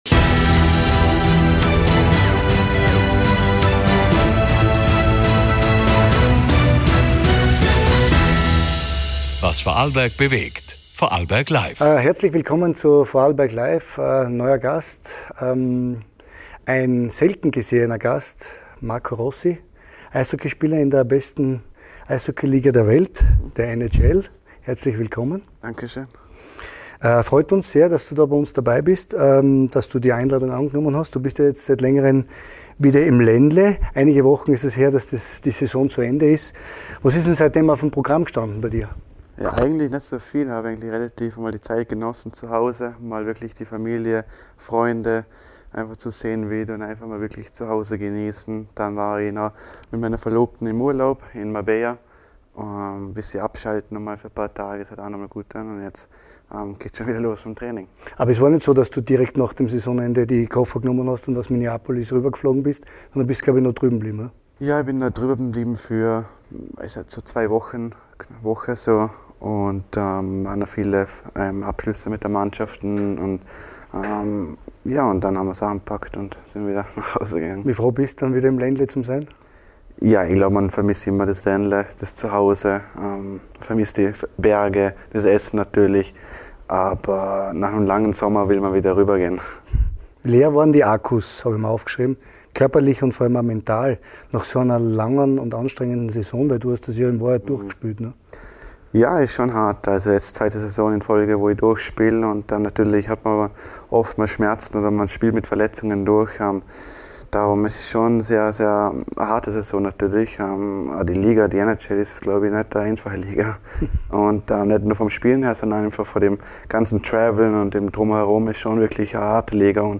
NHL-Star Marco Rossi im Interview ~ Vorarlberg LIVE Podcast